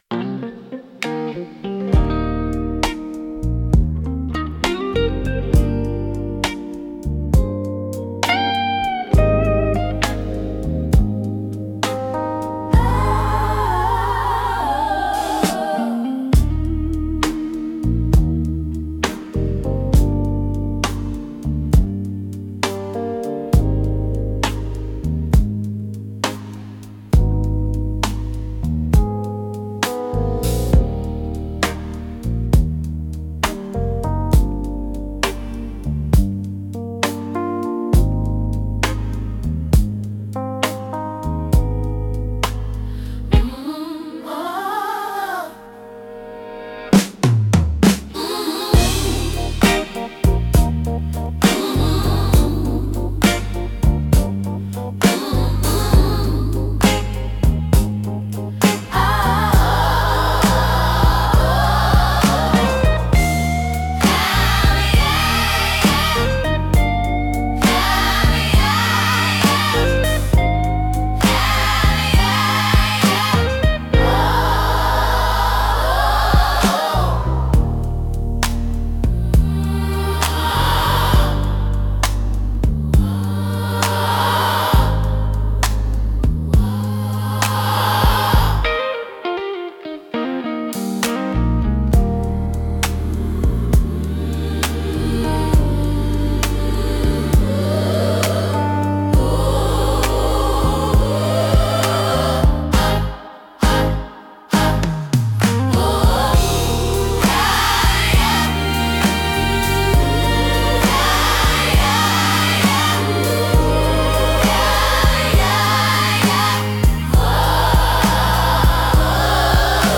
ギターやハーモニカによる哀愁のあるメロディとリズムが、深い感情表現を生み出します。